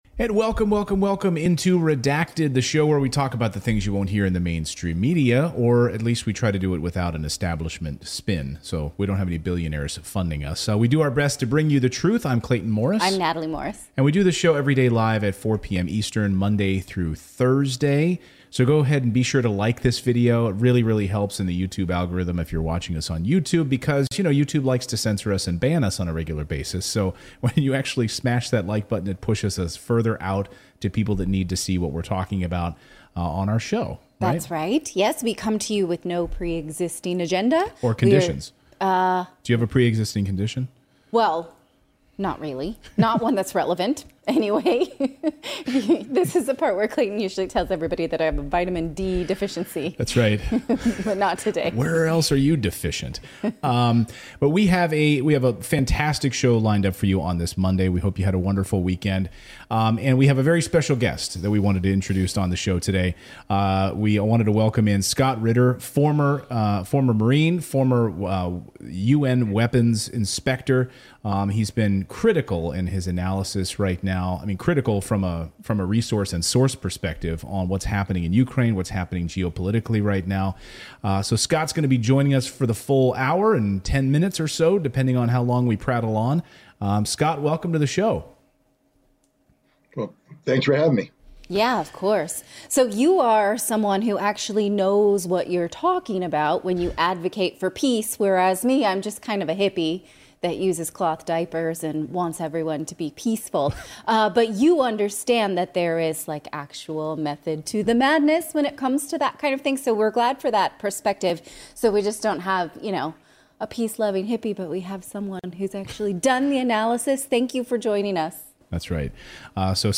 Scott Ritter joins on today's show.